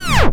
BRAKE SCREE.wav